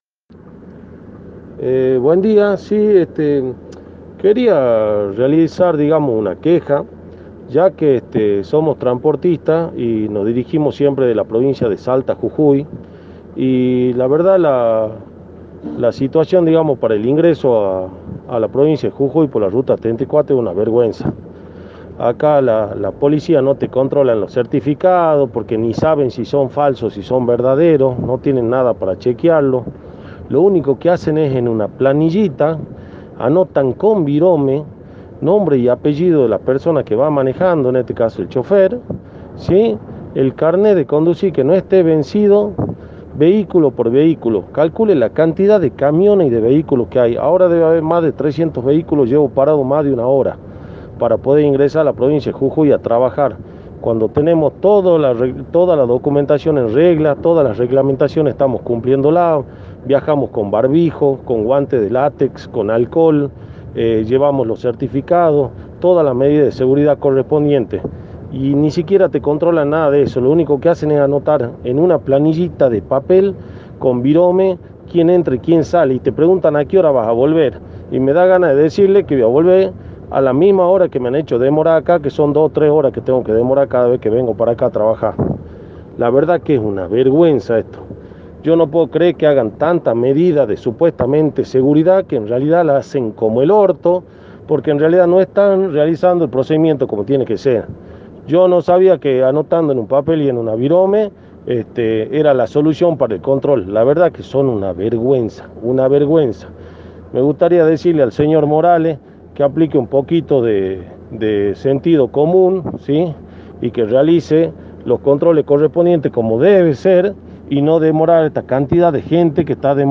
“Somos transportistas que realizamos siempre el tramo Salta Jujuy por la Ruta 34 y la verdad que la situación para el ingreso a la provincia de Jujuy es una vergüenza”, sostuvo un trabajador del rubro en diálogo con Radio Dinamo.